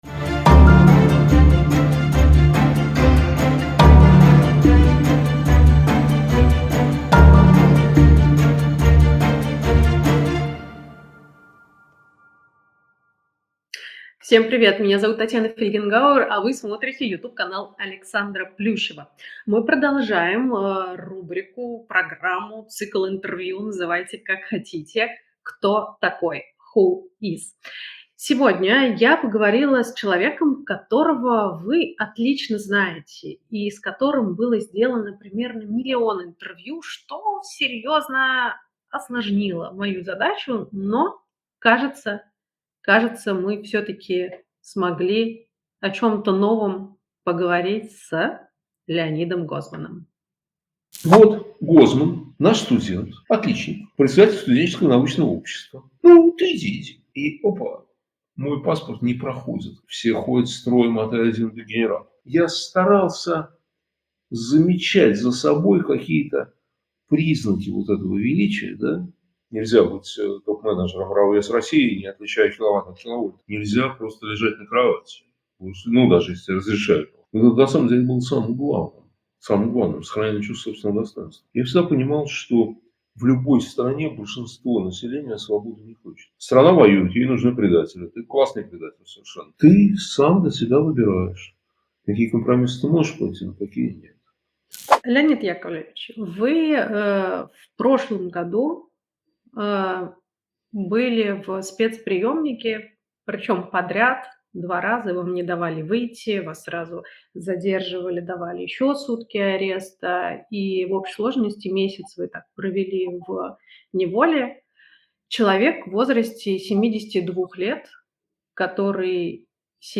Татьяна Фельгенгауэр продолжает цикл портретных интервью. Ее новый герой – публицист Леонид Гозман